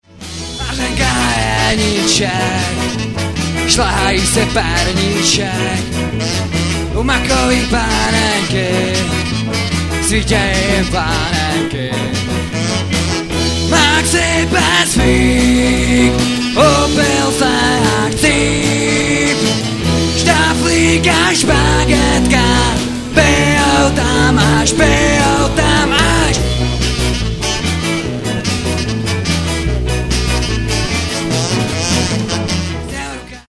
Nahráno živě na koncertu v klubu Prosek dne 22.3.2002